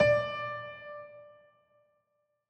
files AfterStory/Doki Doki Literature Club/game/mod_assets/sounds/piano_keys
D5.ogg